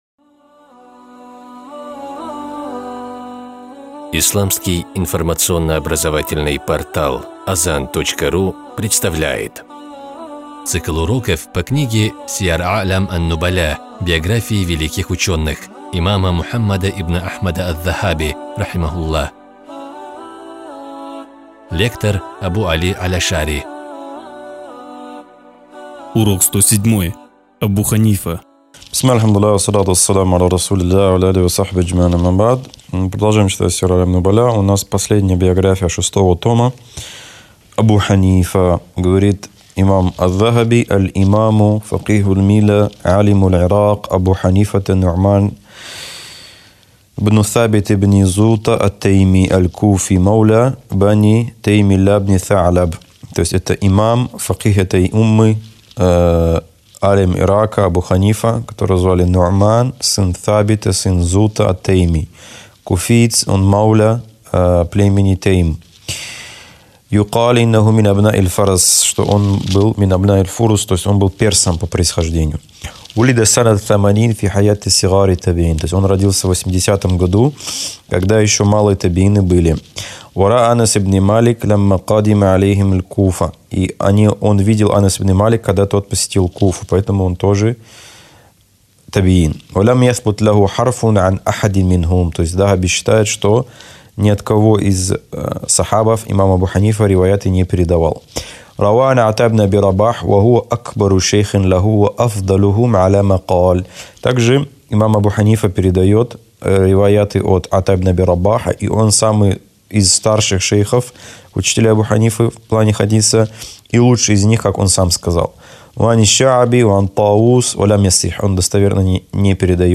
Цикл уроков по книге великого имама Аз-Захаби «Сияр а’лям ан-нубаля».